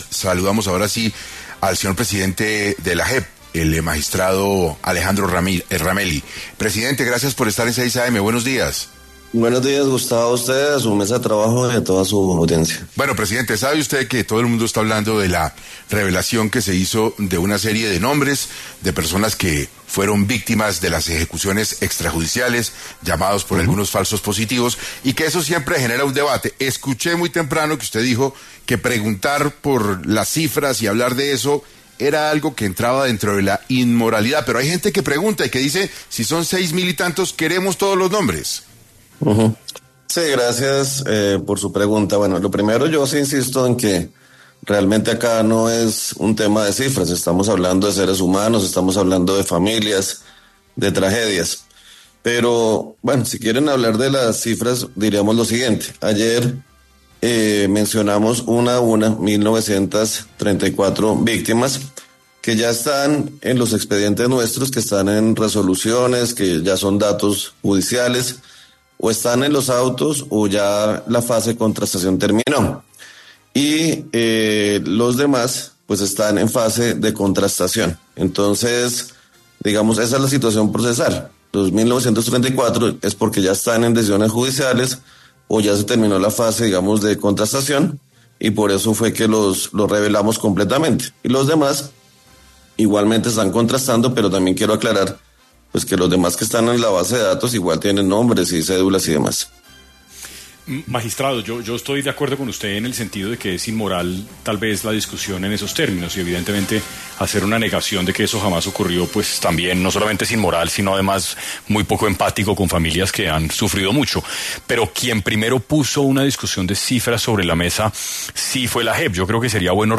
En Caracol Radio estuvo el magistrado Alejandro Ramelli, presidente de la JEP